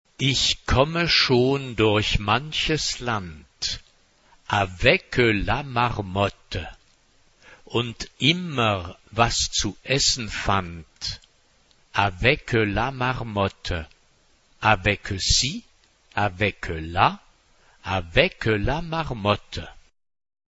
Profane ; Classique
SATB (4 voix mixtes )
Tonalité : sol mineur